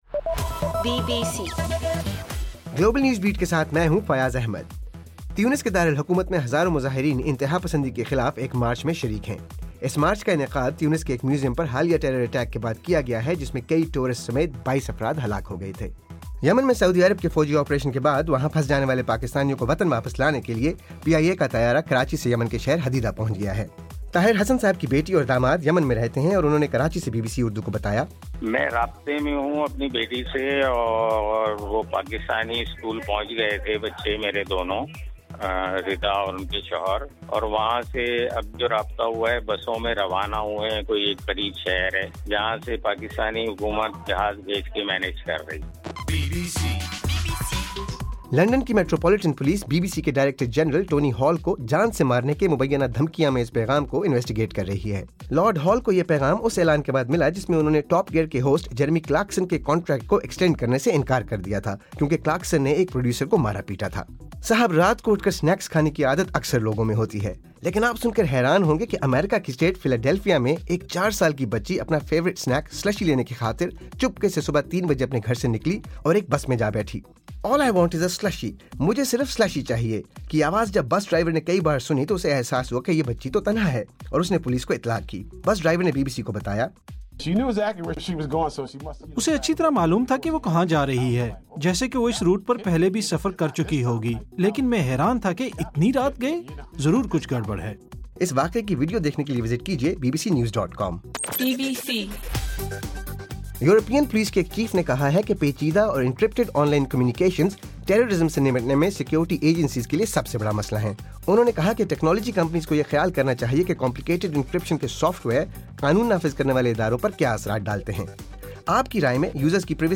مارچ 29: رات 9 بجے کا گلوبل نیوز بیٹ بُلیٹن